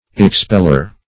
Expeller \Ex*pel"ler\, n. One who, or that which, expels.